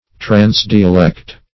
Search Result for " transdialect" : The Collaborative International Dictionary of English v.0.48: Transdialect \Trans*di"a*lect\, v. t. [Pref. trans- + dialect.]